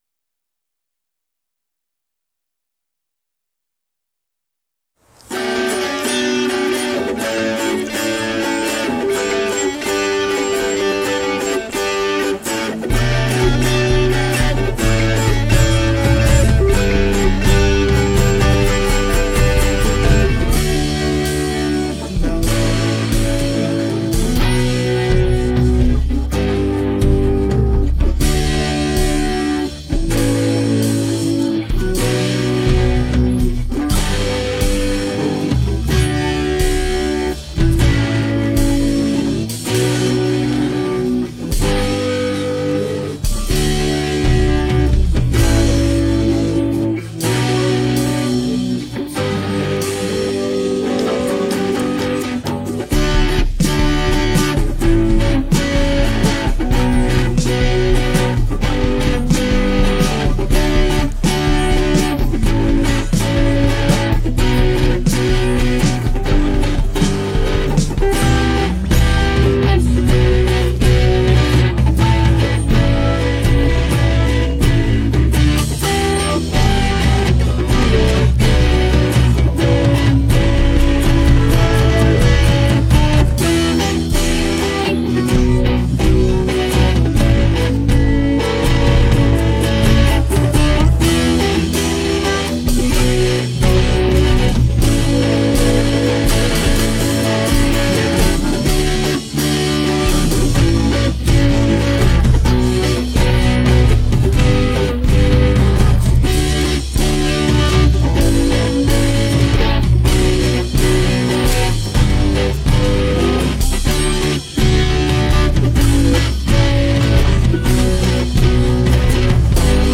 Awit-ng-Pag-ibig-Siglo-minus-one.mp3